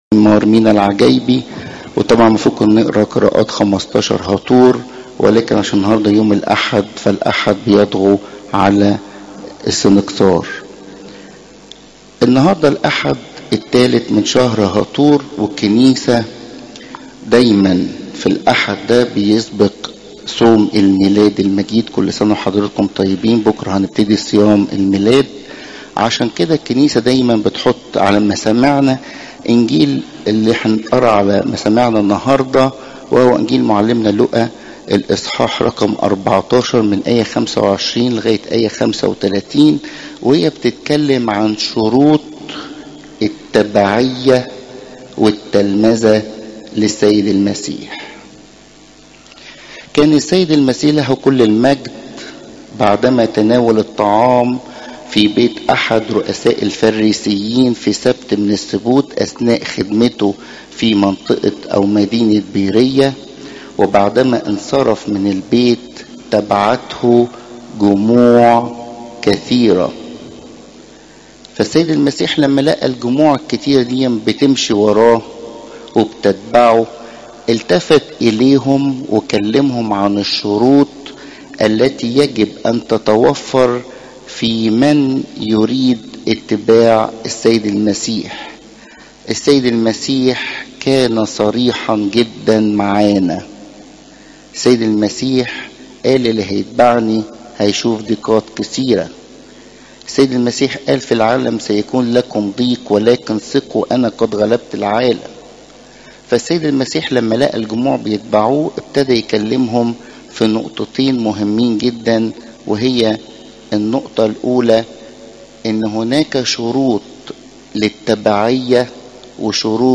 عظات قداسات الكنيسة (لو 14 : 25 - 35)